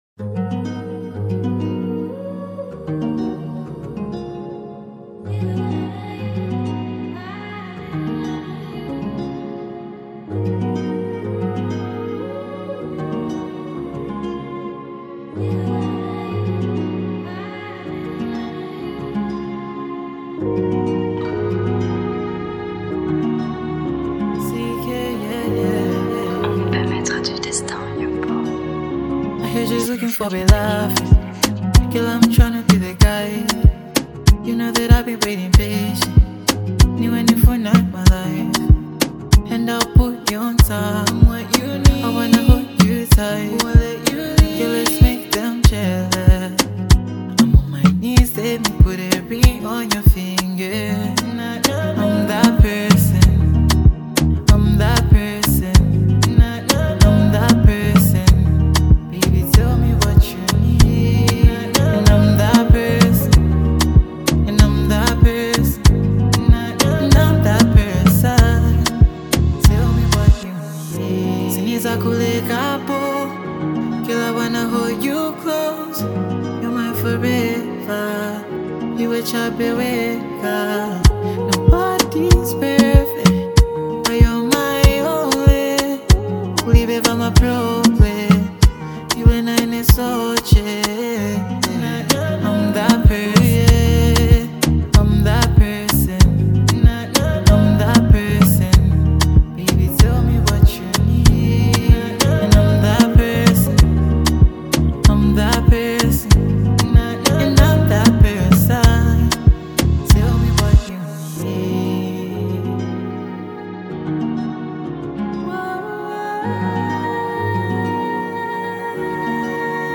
blending emotion with an irresistible beat.
smooth vocals